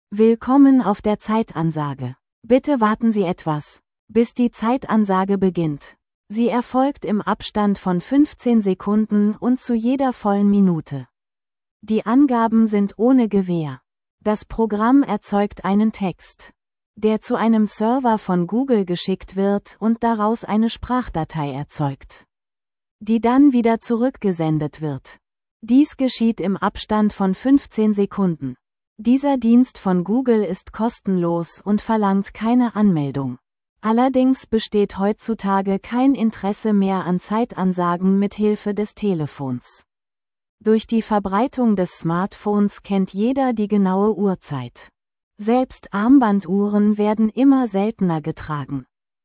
Klangbeispiele: Google gTTS in seiner kostenlosen Versionen ist nur für kurze Sätze geeignet, da es sich sonst oft in der Betonung vergreift.
Diese wird zunächst als MP3 erstellt und dann mit Hilfe von ffmpeg in eine WAV-Datei mit Asterisk-kompatiblen Einstellungen (8 kHz, Mono) umgewandelt.
zeitansage.wav